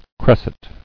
[cres·set]